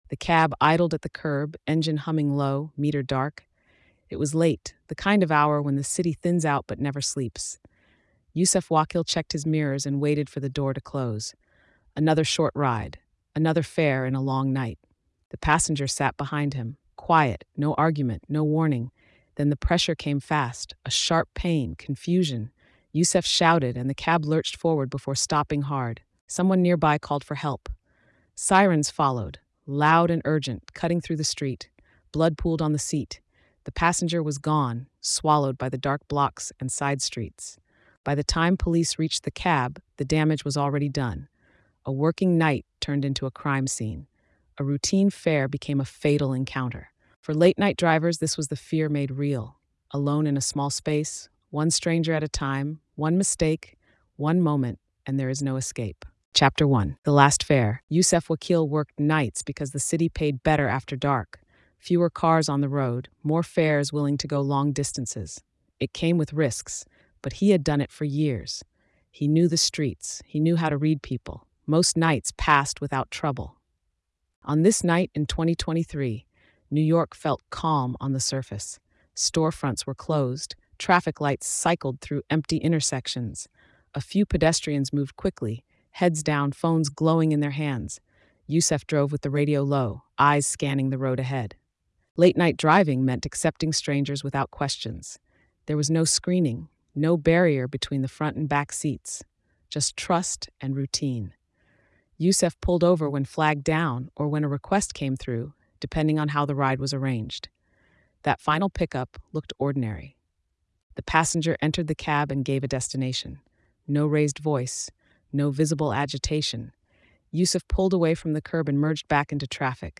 Told in a forensic, documentary style, the story follows the final moments of an ordinary shift that turned deadly, the investigation that followed, and the wider danger faced by drivers who work alone after dark. It is a grounded account of sudden violence, investigative pressure, and the cost of keeping a city moving while most people sleep.